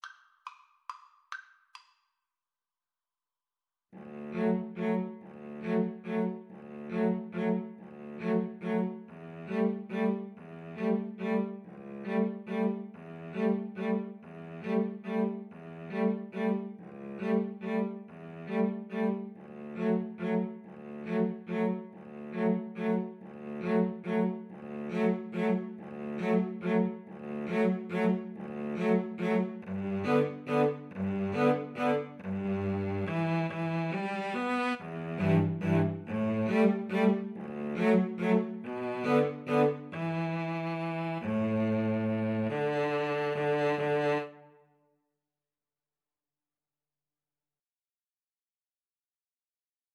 Free Sheet music for Cello Duet
D major (Sounding Pitch) (View more D major Music for Cello Duet )
3/4 (View more 3/4 Music)
=140 Slow one in a bar
Classical (View more Classical Cello Duet Music)